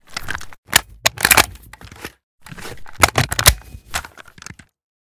svd_reload.ogg